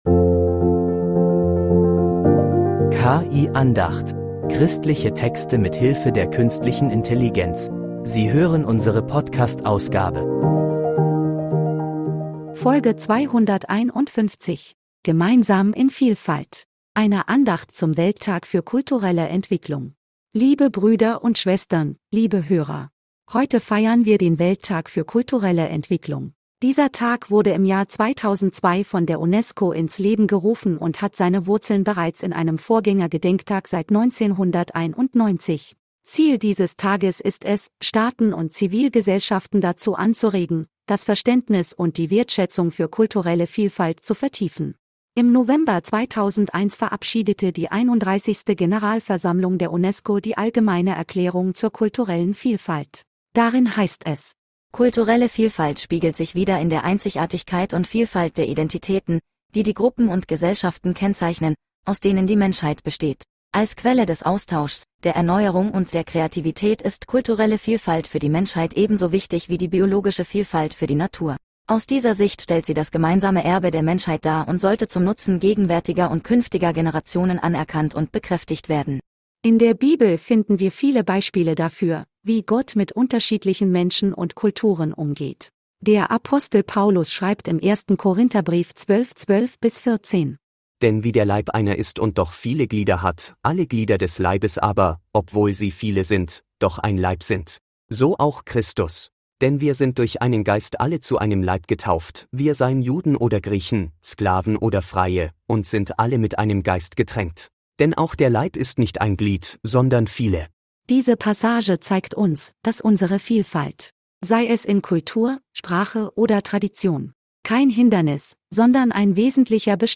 Eine Andacht zum Welttag für kulturelle Entwicklung